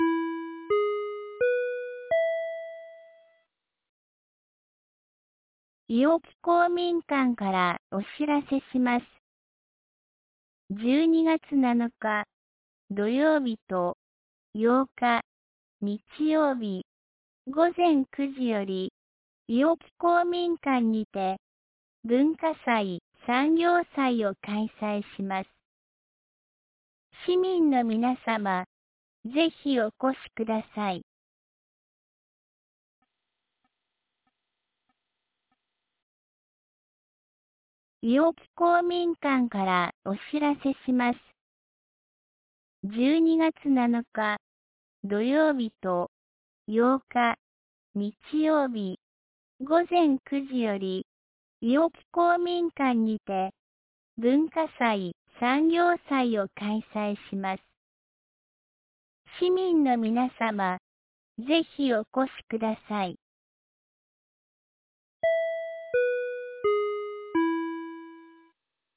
2024年12月06日 17時11分に、安芸市より全地区へ放送がありました。